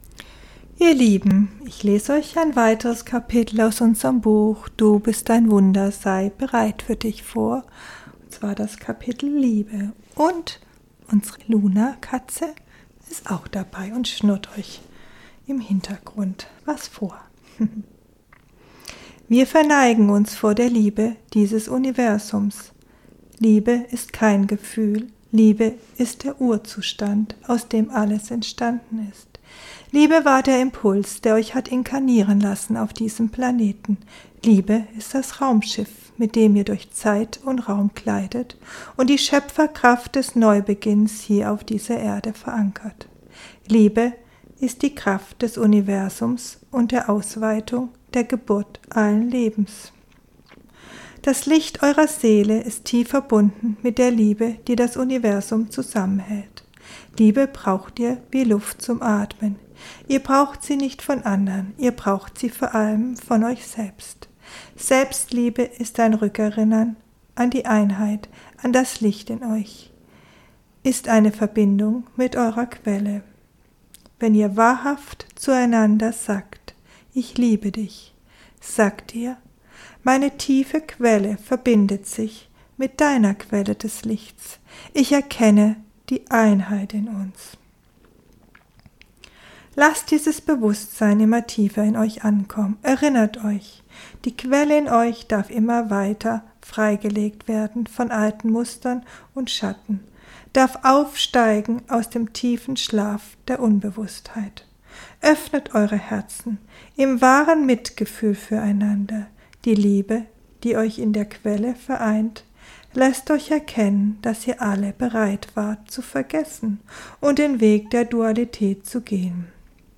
Ich lese dir vor….